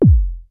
VCF BASE 2 1.wav